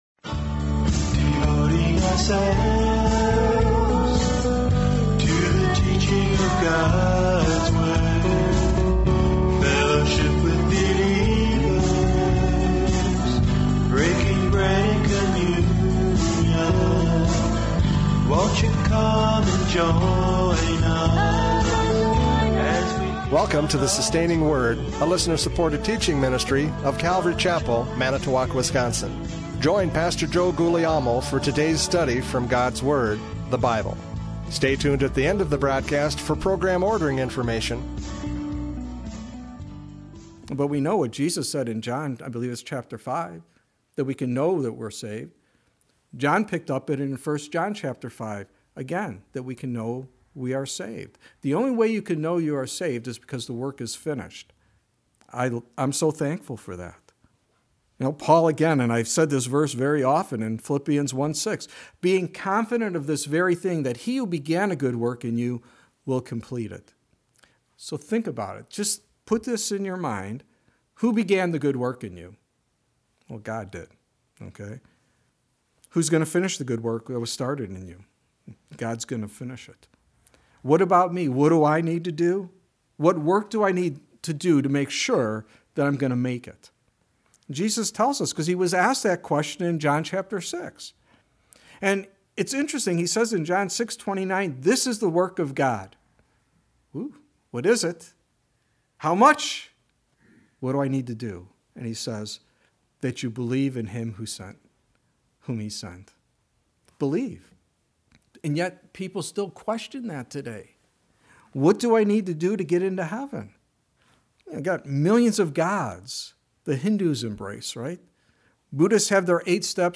John 14:22-31 Service Type: Radio Programs « John 14:22-31 The Teacher!